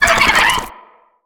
Sfx_creature_penguin_flinch_land_01.ogg